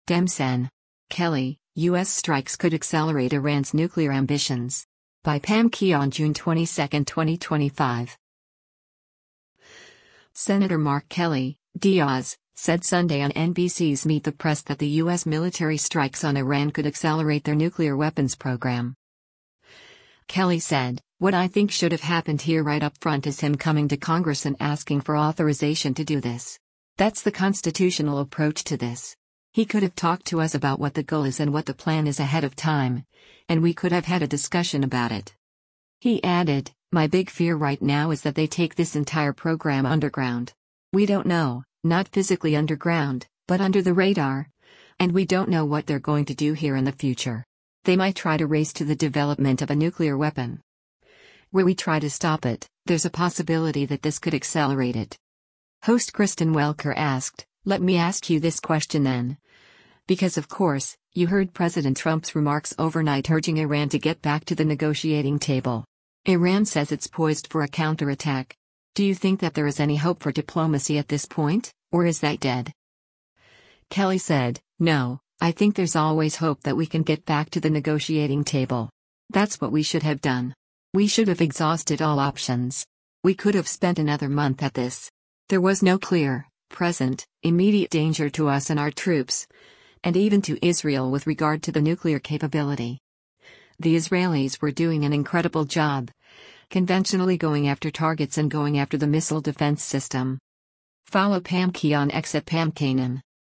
Senator Mark Kelly (D-AZ) said Sunday on NBC’s “Meet the Press” that the U.S. military strikes on Iran “could accelerate” their nuclear weapons program.